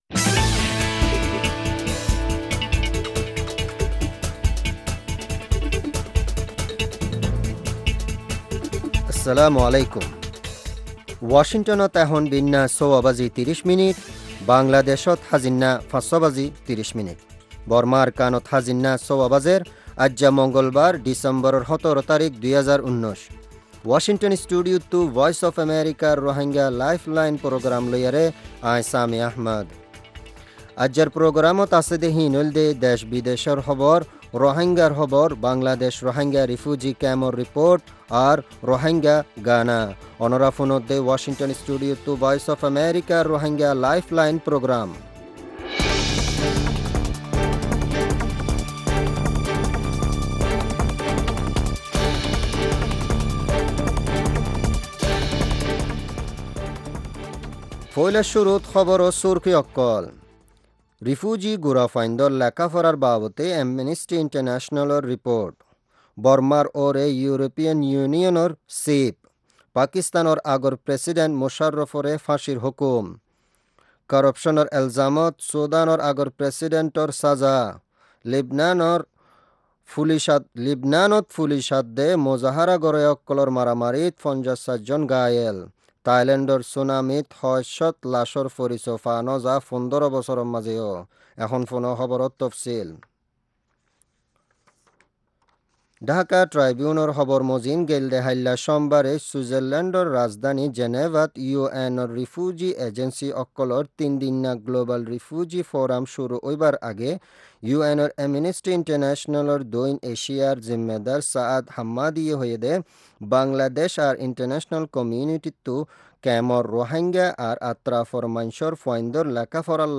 News headlines: ● International community must share responsibility for educating Rohingya children ● European Union may revoke Myanmar’s GSP status, ending preferential trade tariff rates ● Former Pakistani President Musharraf sentenced to death ● Ex-president Bashir of Sudan sentenced for corruption ● Anti-government protests continue in Lebanon ● Hundreds of Thai tsunami victims remain unidentified after 15 years Topic: Several Rohingya IDPs talk about health issues in Cox’s Bazar settlements